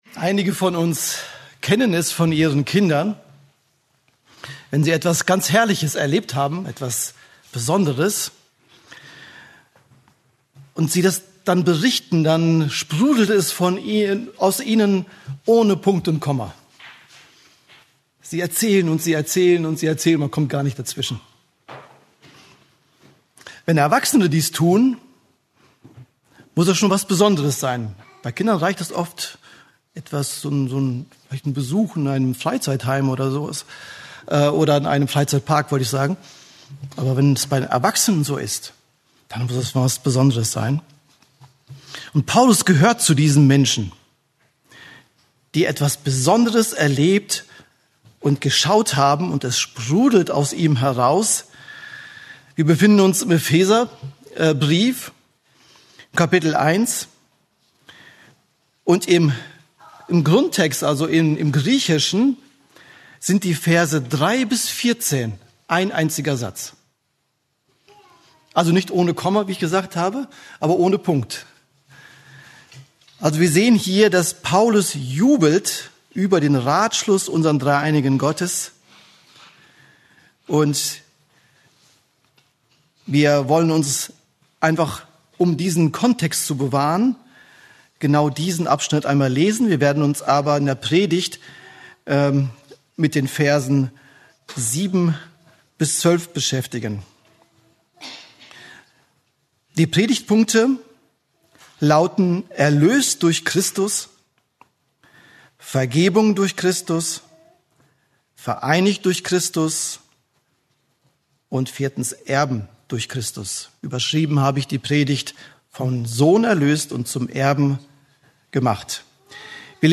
Eine predigt aus der serie "Epheser."